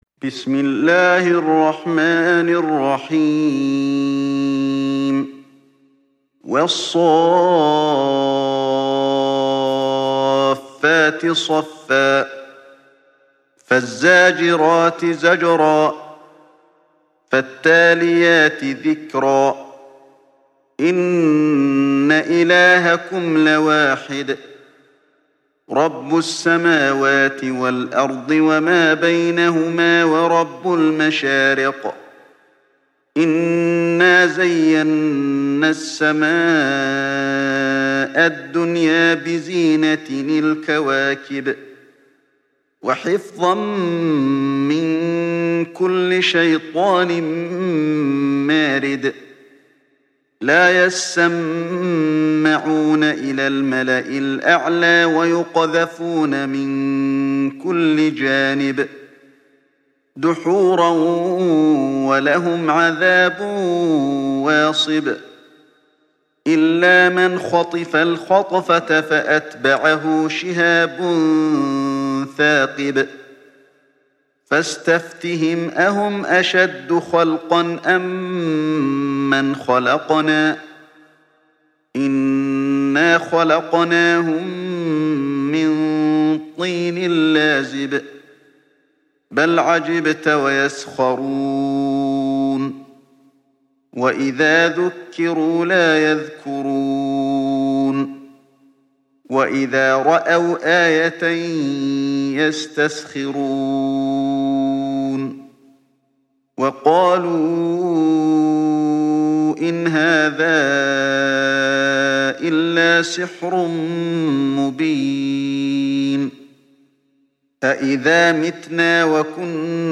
تحميل سورة الصافات mp3 بصوت علي الحذيفي برواية حفص عن عاصم, تحميل استماع القرآن الكريم على الجوال mp3 كاملا بروابط مباشرة وسريعة